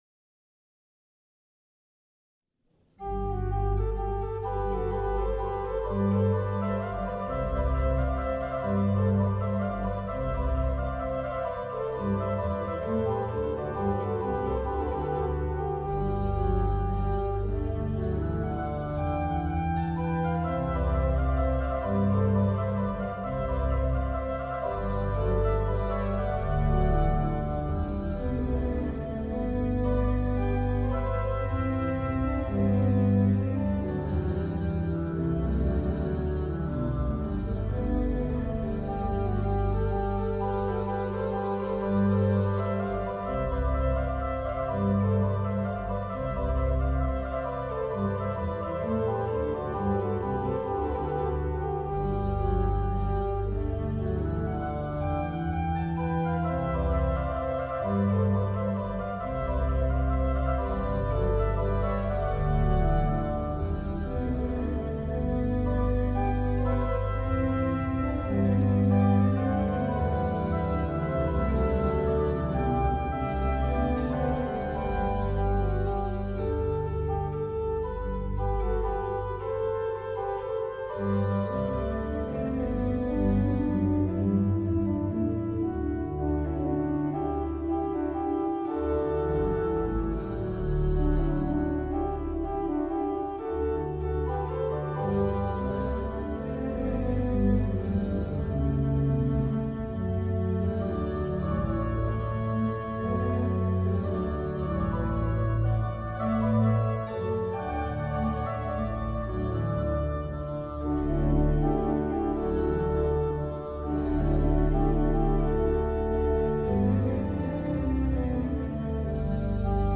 Keyboard Works
Koraalzetting